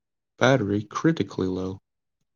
battery-critically-low.wav